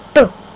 Boo! Sound Effect
Download a high-quality boo! sound effect.
boo-2.mp3